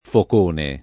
[ fok 1 ne ]